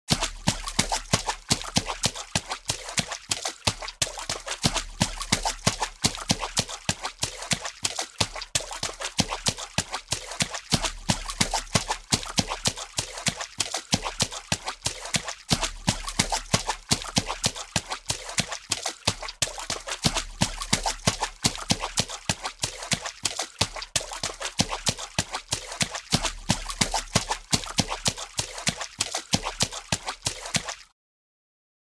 Звуки лужи
Шлепанье ног по лужам